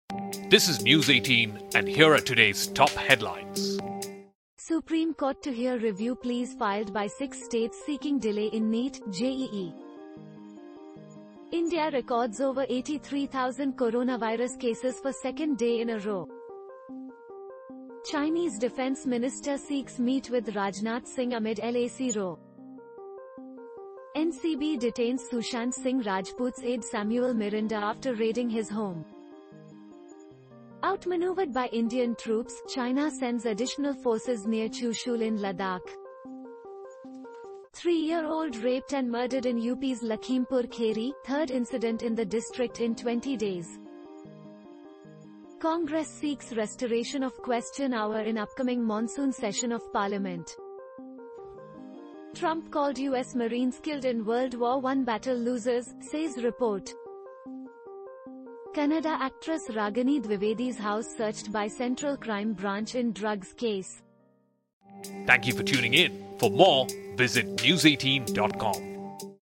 News18 Audio Bulletin: Review Pleas on NEET, JEE Dates in Supreme Court Today and Other Top Headlines in 2 Minutes